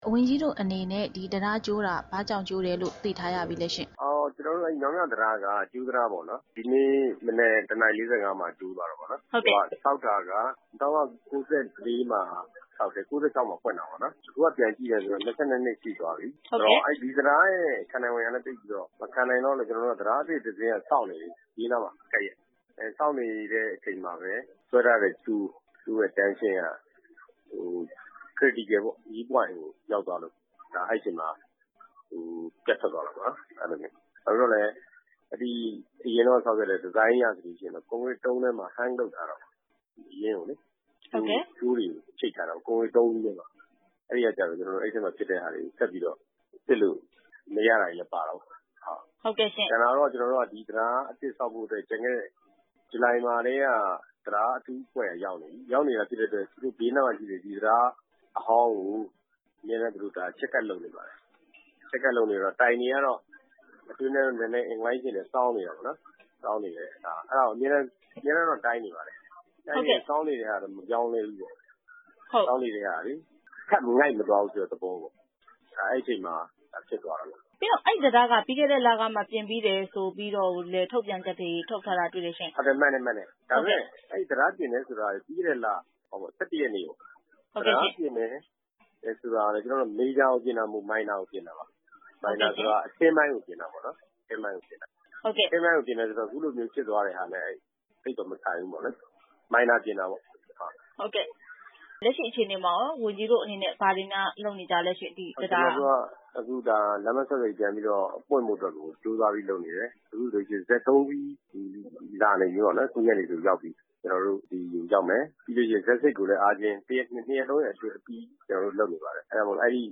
မြောင်းမြကြိုးတံတားပျက်ကျမှု ဆက်သွယ်မေးမြန်းချက်